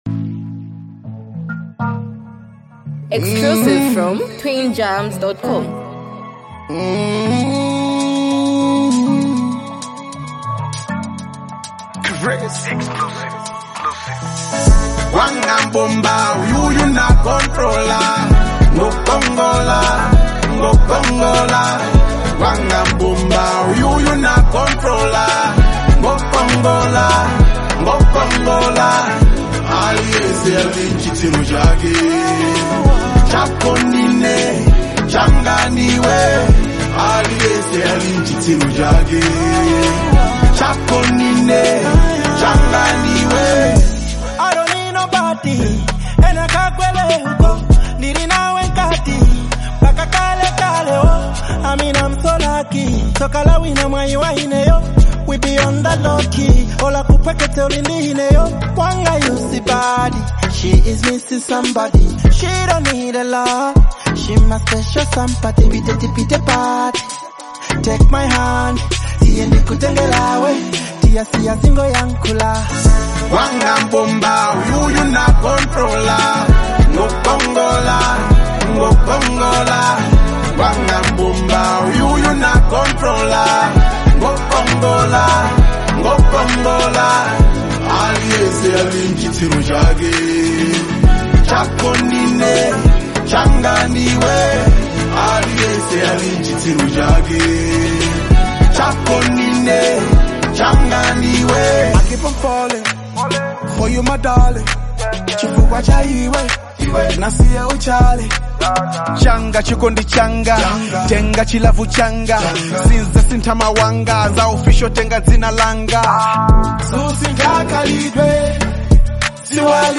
a fun, high-energy song